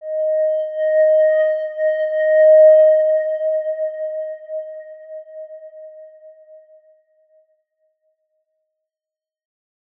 X_Windwistle-D#4-ff.wav